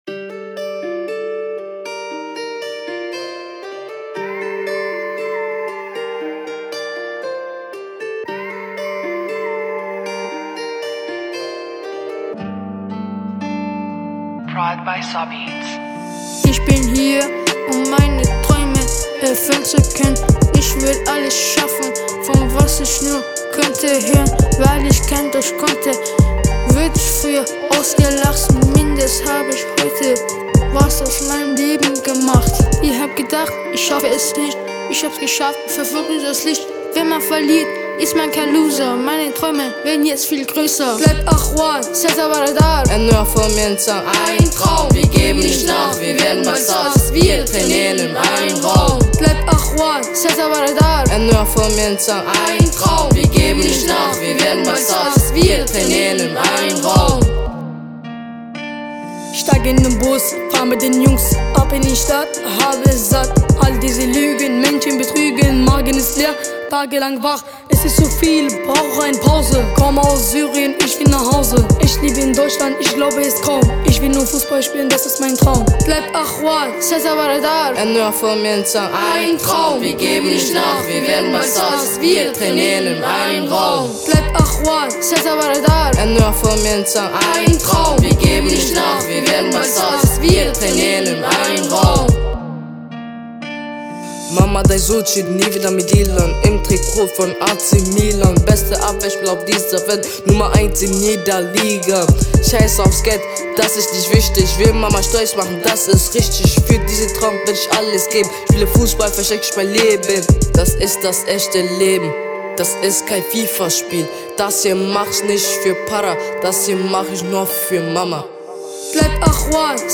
In nur jeweils drei Tagen haben 6 junge Menschen im Rahmen des Projektes „In Action“- startklar in die Zukunft einen RAP-Song entwickelt, d.h. einen gemeinsamen Refrain und ihre eigene Strophe geschrieben, das Ganze zu einem ausgewählten Beat gerappt bzw. gesungen und im Studio des Musikzentrums unter professionellen Bedingungen aufgenommen.